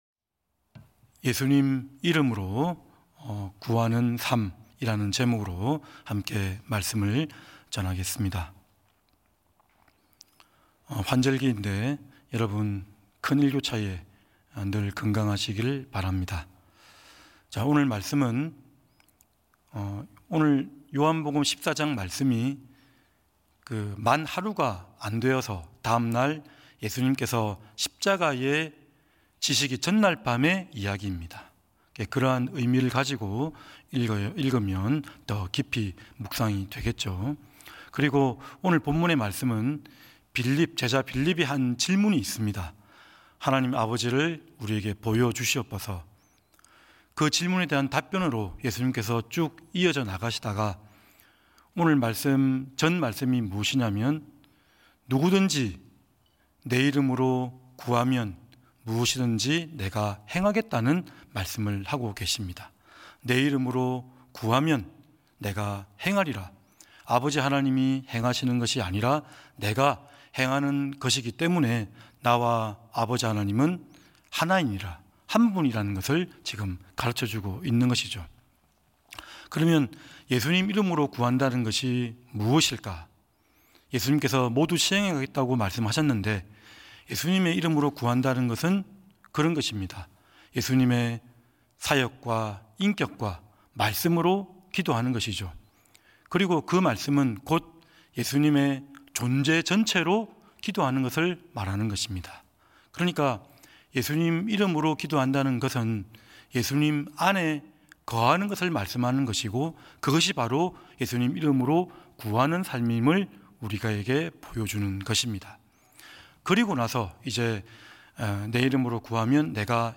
예배 새벽예배